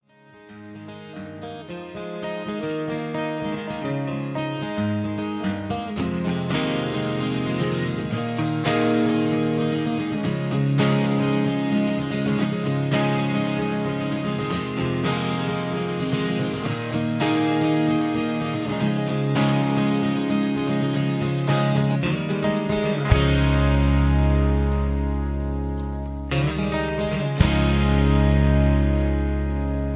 Tags: karaoke , backing tracks , soundtracks , rock